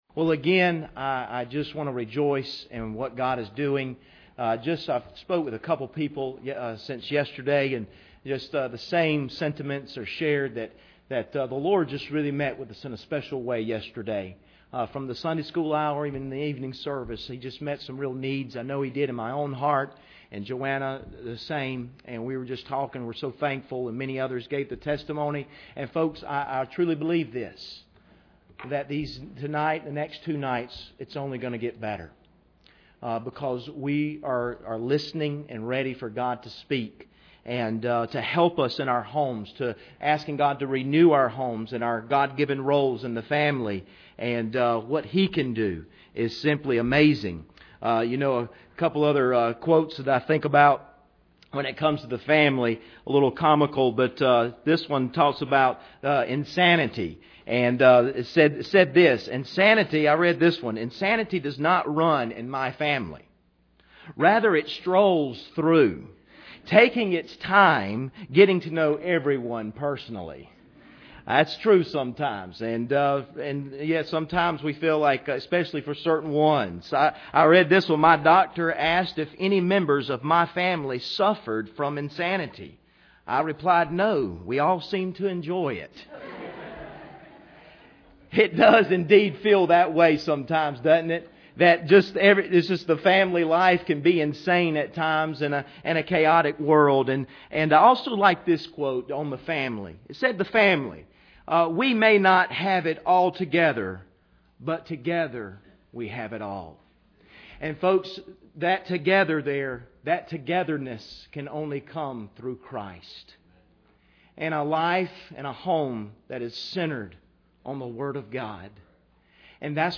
Series: 2017 Family Conference
1 Corinthians 11:28 Service Type: Sunday Evening Bible Text